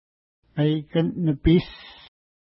ID: 466 Longitude: -63.1082 Latitude: 53.5491 Pronunciation: meikən-nəpi:s Translation: Wolf Lake (small) Feature: lake Explanation: Named in reference to bigger lake Maikan-nipi (no 463) to which it connects.